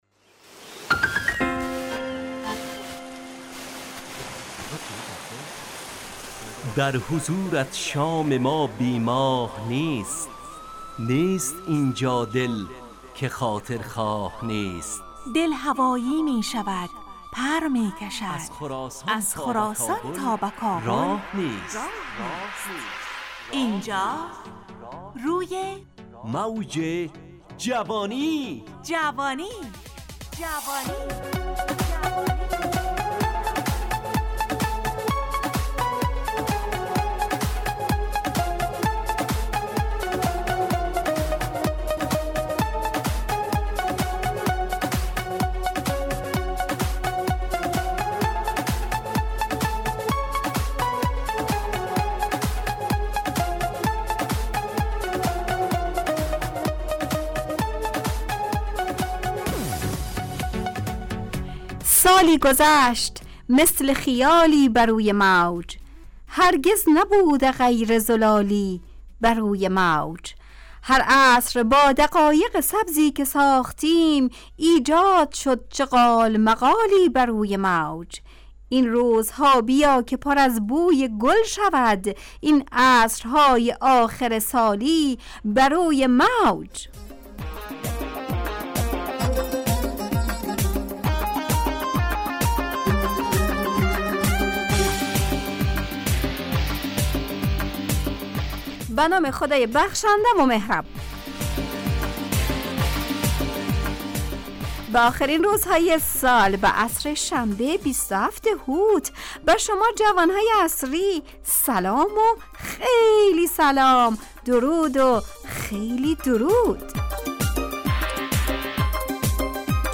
همراه با ترانه و موسیقی مدت برنامه 55 دقیقه .